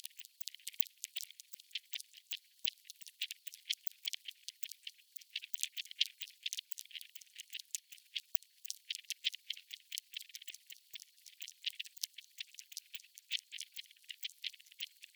chewing.mp3